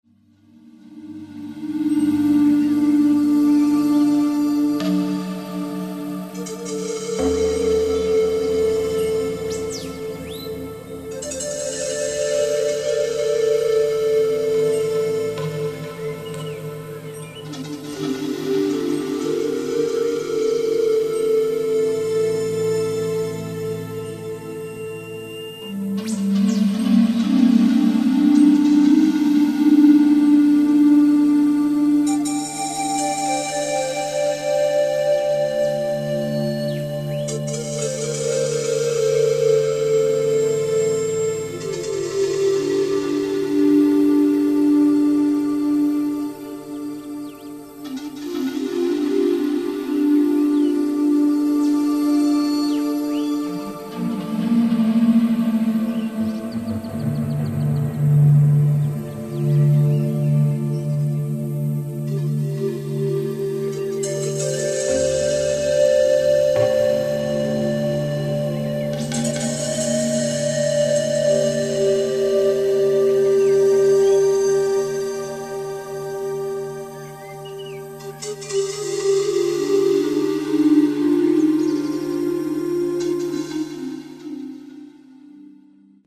Muzyka bez opłat
Płyta zawiera wspaniałe utwory oparte na dźwiękach fletni.